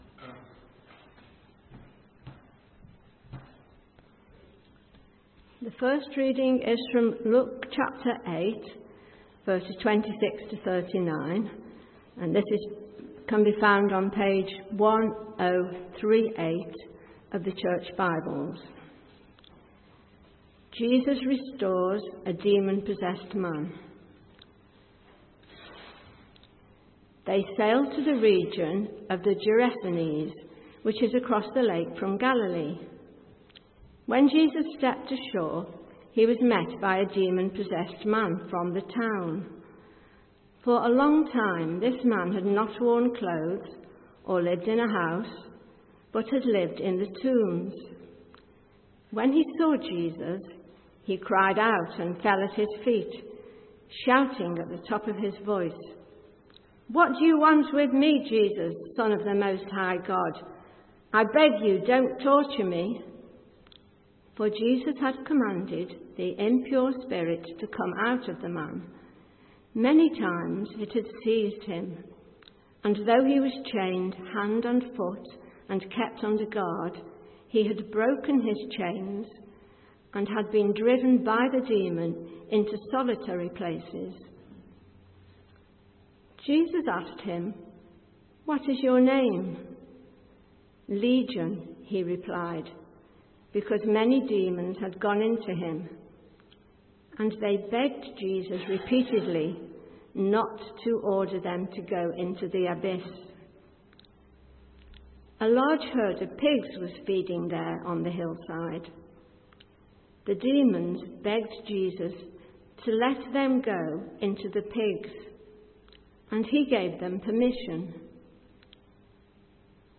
A message from the series
From Service: "9.00am Service"